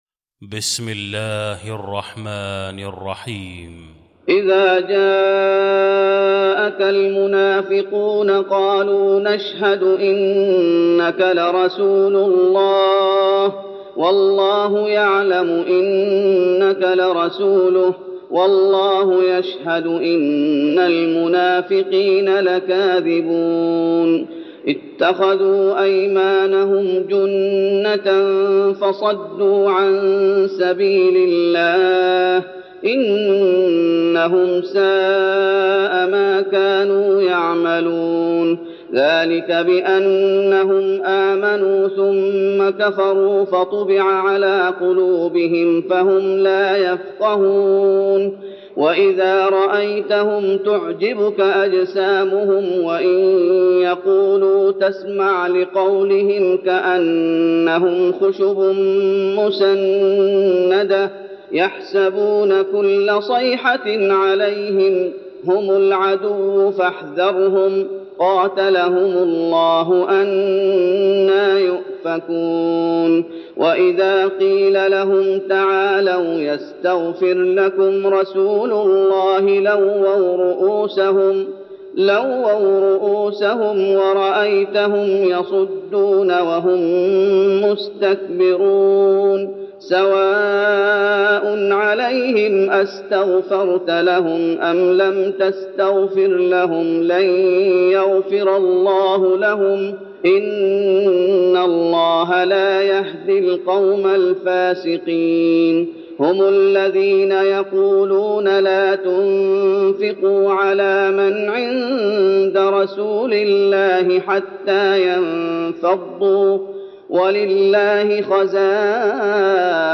المكان: المسجد النبوي المنافقون